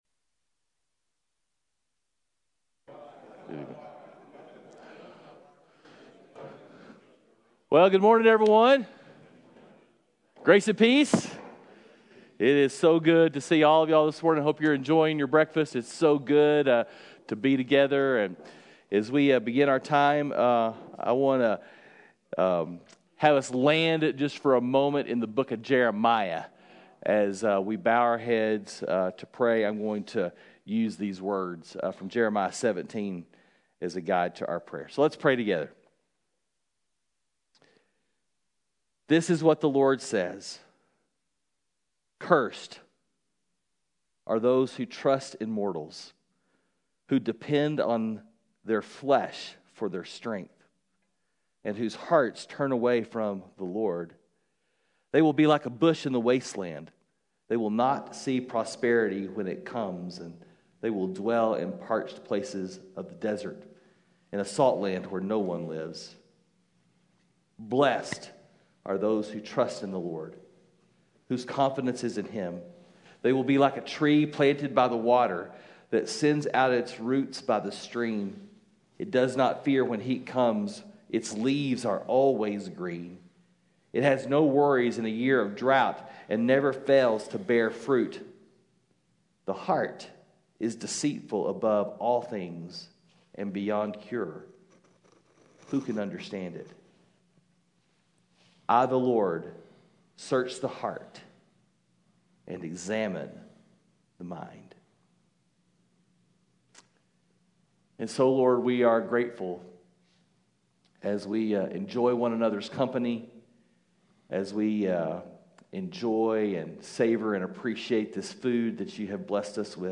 Men’s Breakfast Bible Study 8/25/20